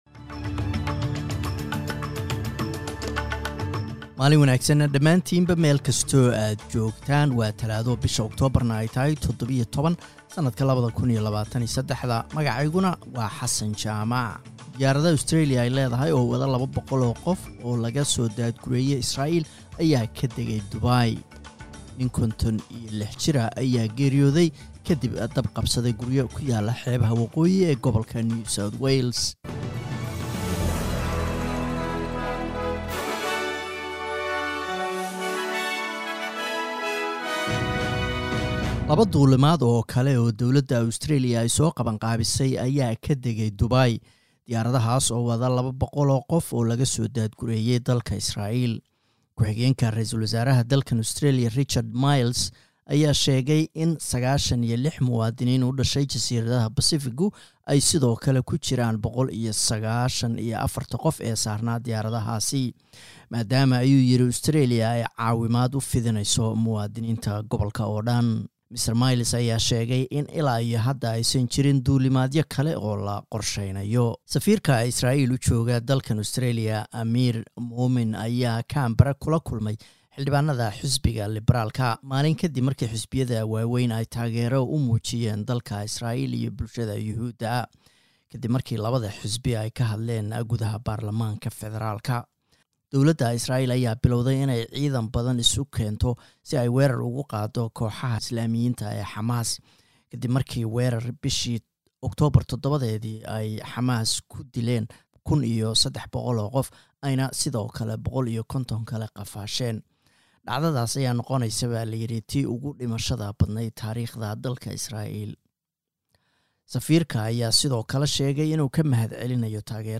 Wararka SBS Somali